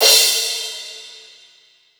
Crashes & Cymbals
J_CRASH.WAV